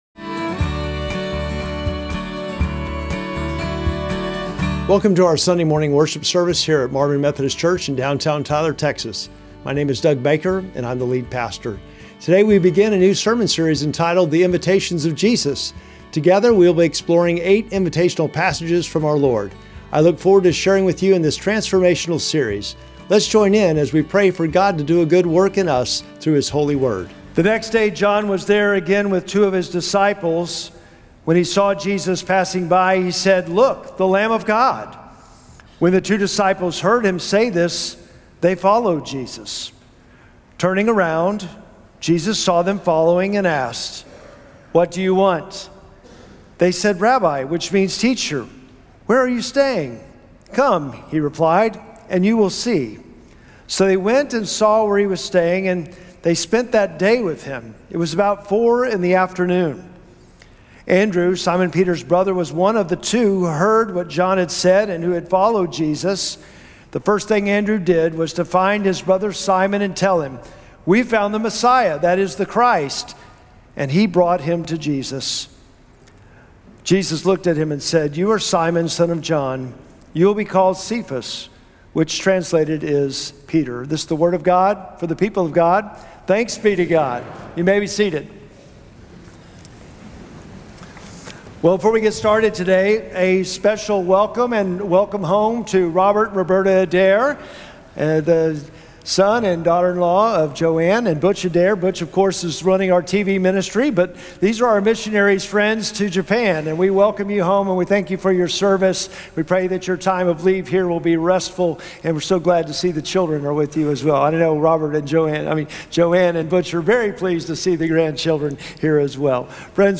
Sermon text: John 1:35-42